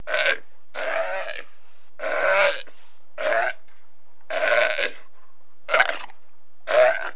دانلود صدای حیوانات جنگلی 86 از ساعد نیوز با لینک مستقیم و کیفیت بالا
جلوه های صوتی